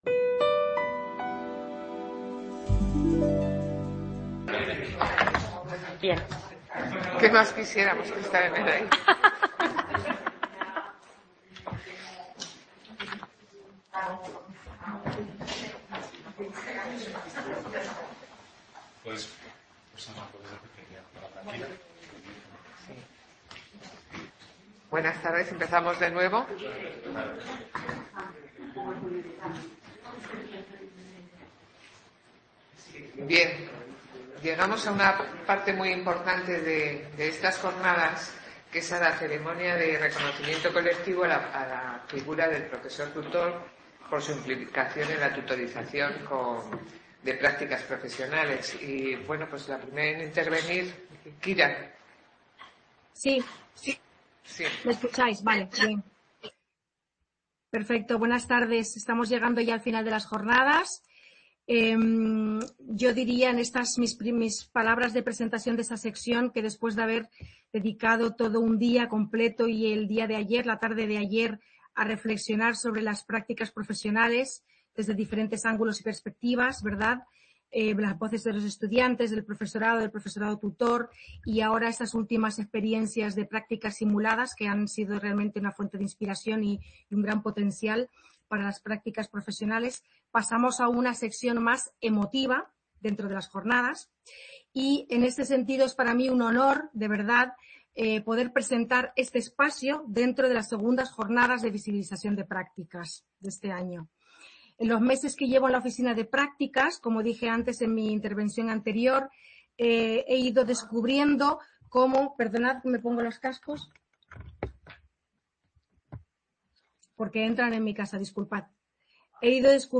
/ Acto de clausura.